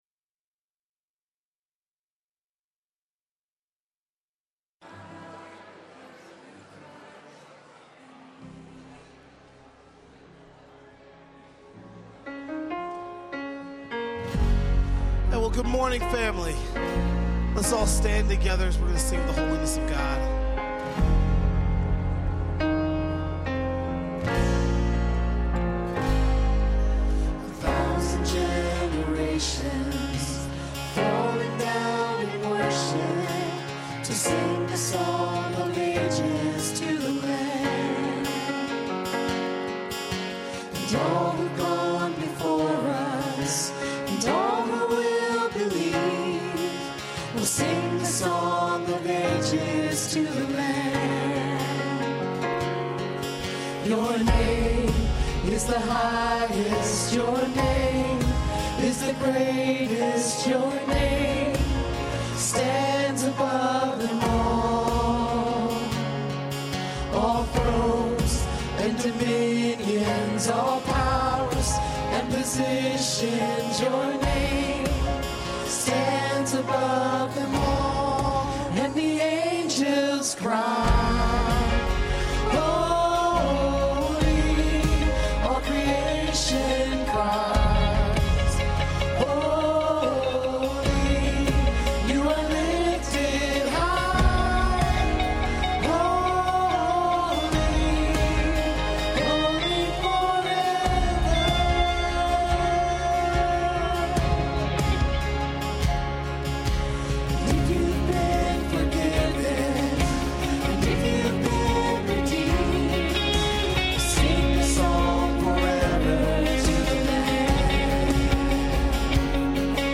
A message from the series "Behind the Veil."